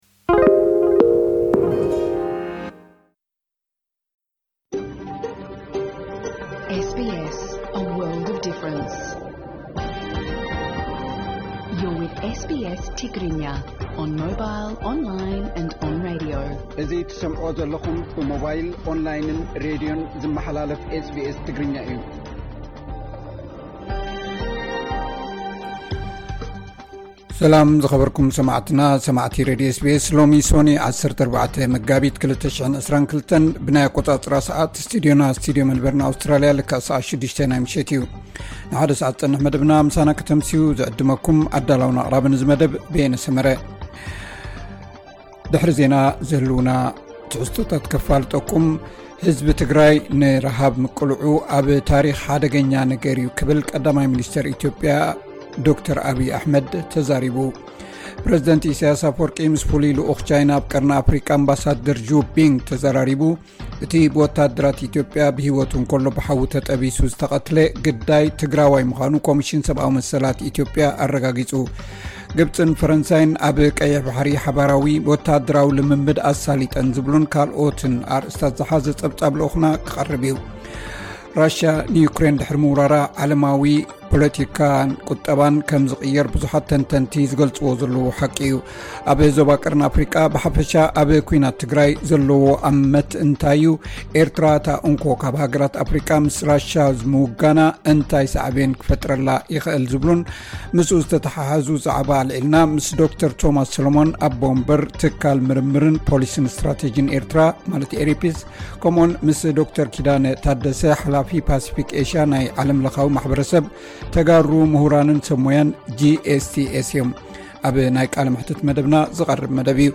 ቀጥታ ፈነወ SBS ትግርኛ 14 መጋቢት 2022